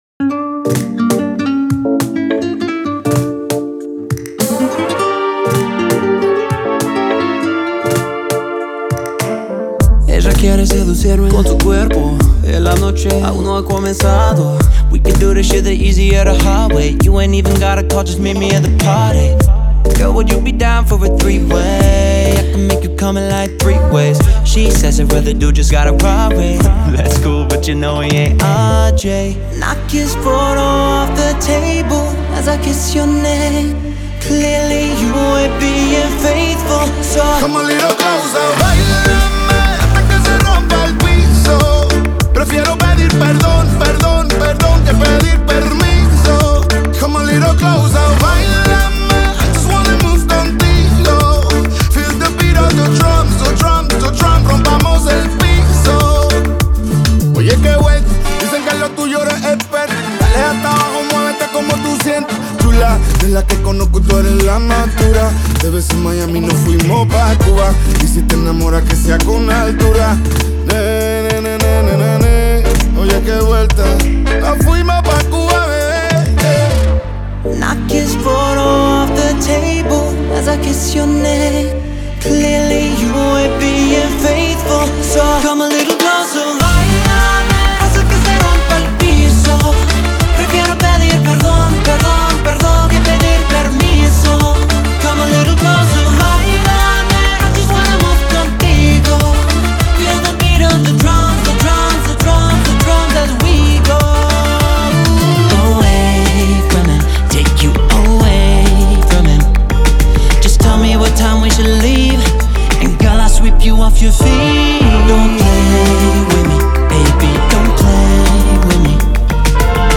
это зажигательная трек в жанре латинской поп-музыки